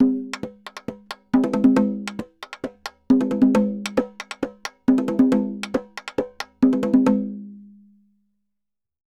Tambora_Merengue 136-2.wav